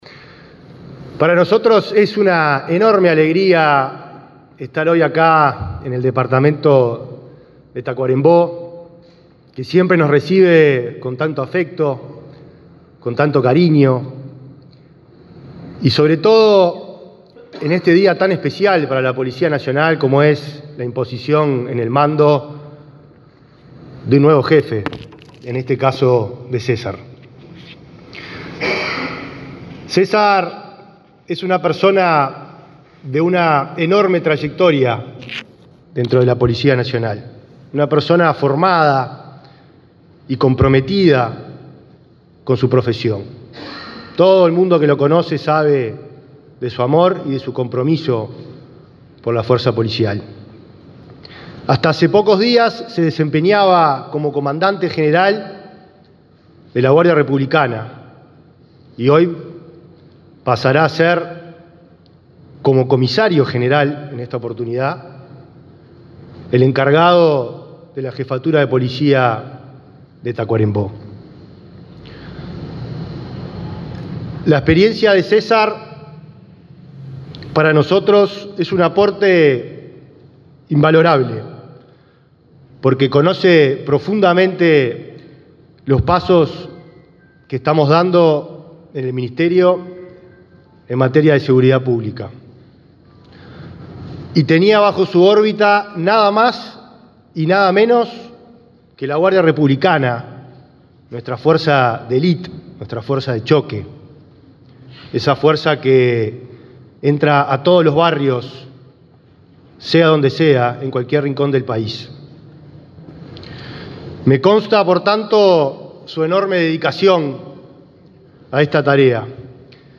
Palabras del ministro del Interior, Nicolás Martinelli
Este lunes 27, el ministro del Interior, Nicolás Martinelli, impuso en el cargo al nuevo director de Policía de Tacuarembó, César Tourn.